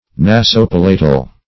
Search Result for " nasopalatal" : The Collaborative International Dictionary of English v.0.48: Nasopalatal \Na`so*pal"a*tal\, Nasopalatine \Na`so*pal"a*tine\, a. [Naso- + palatal.]